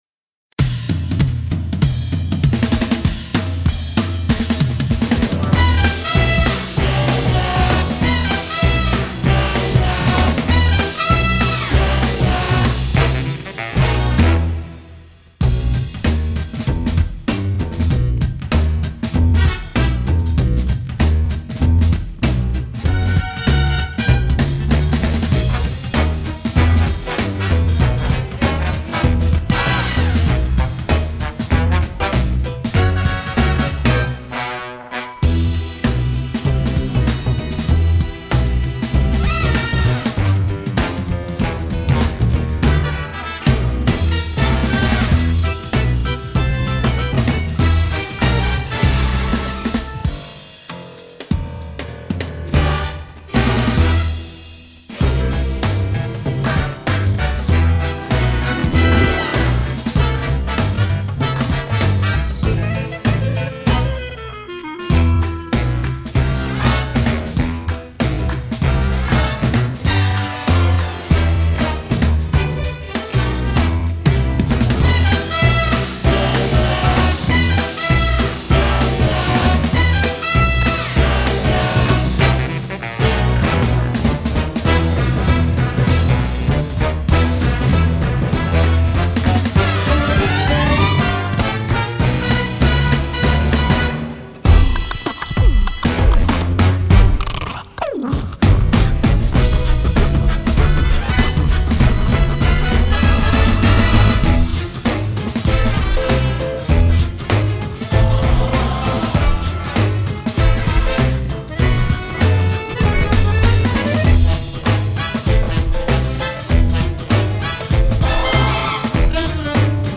Friend Like Me Backing (E Minor) | Ipswich Hospital Community Choir